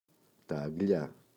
αγγλιά, τα [a’ŋgʎa]